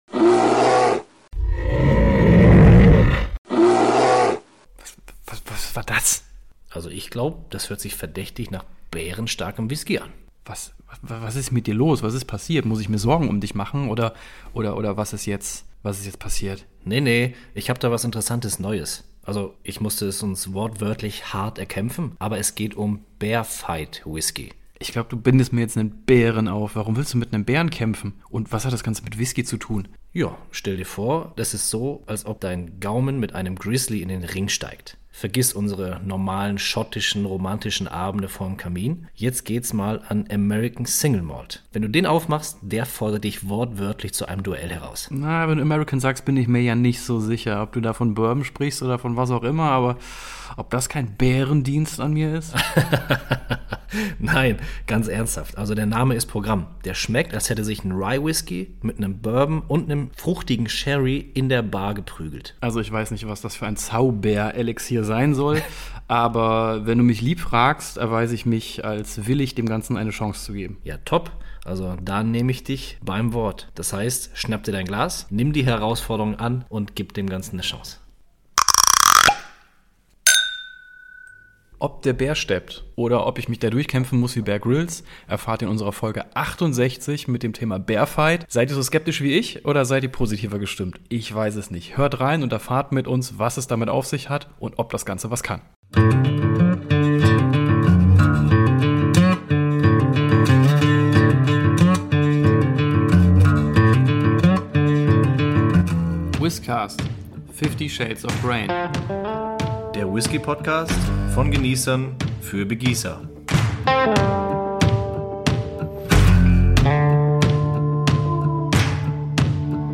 Freut euch auf eine doppelte Dosis Durst, jede Menge Anekdoten und natürlich das gemeinsame Tasting vor laufendem Mikro.